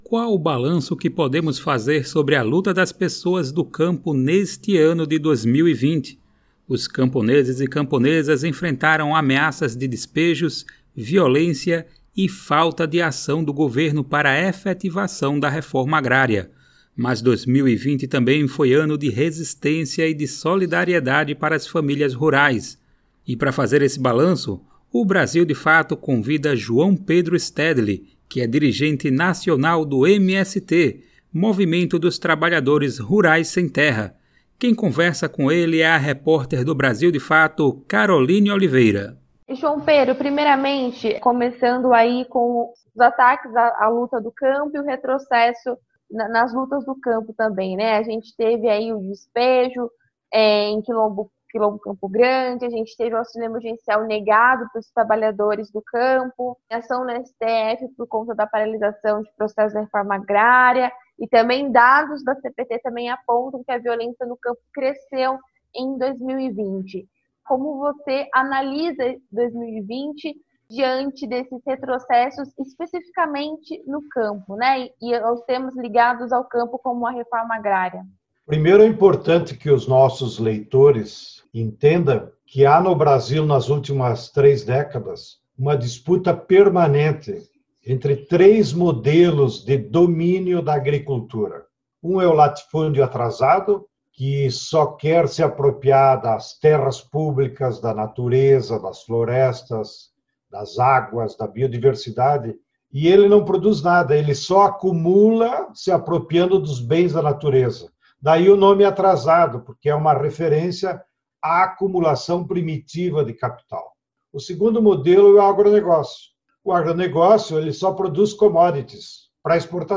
Entrevista Stedile: 2021 vai ser o ano da vacina, da luta social e de mudanças na América Latina -